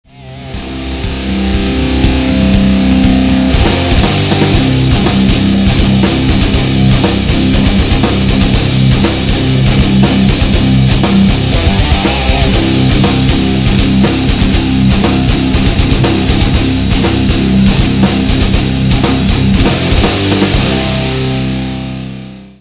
• Качество: 192, Stereo
без слов
электрогитара
Hard rock